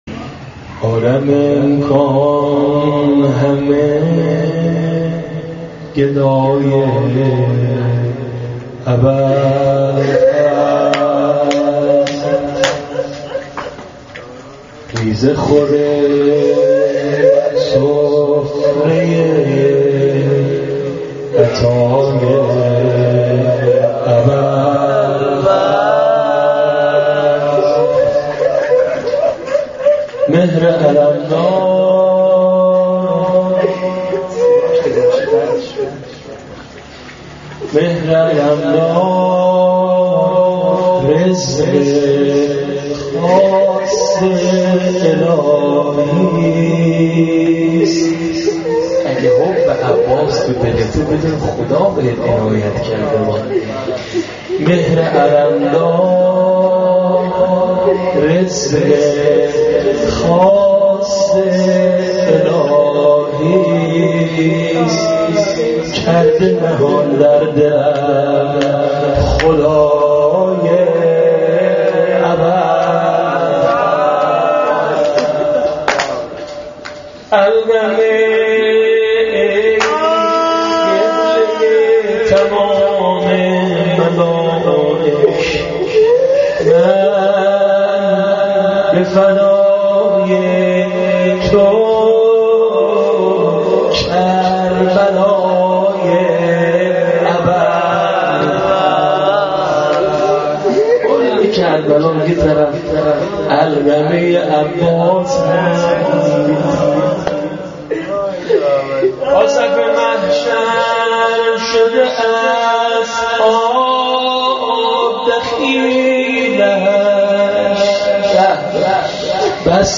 عالم امکان همه گدای ابالفضل-----مدح و روضه ابالفضل.MP3
عالم-امکان-همه-گدای-ابالفضل-مدح-و-روضه-ابالفضل.mp3